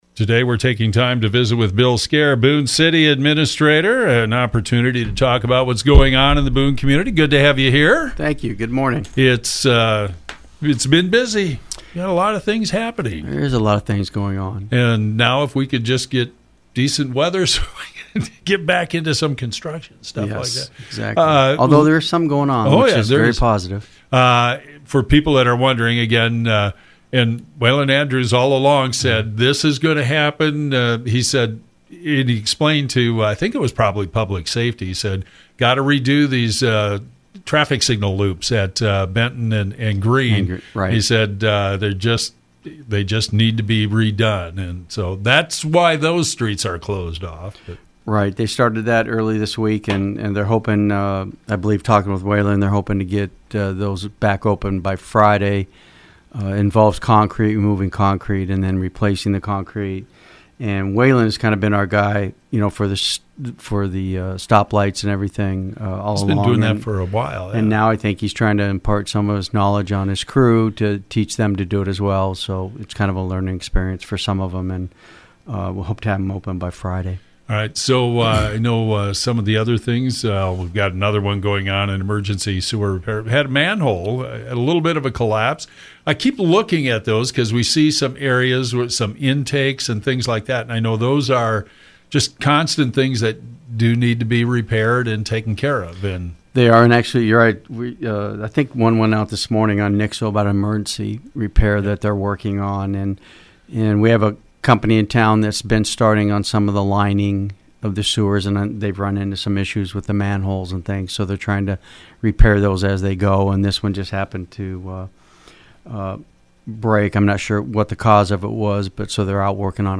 Bill Skare, Boone City Administrator talks about projects underway which will lead to some budget amendments in May, progress on housing projects and more.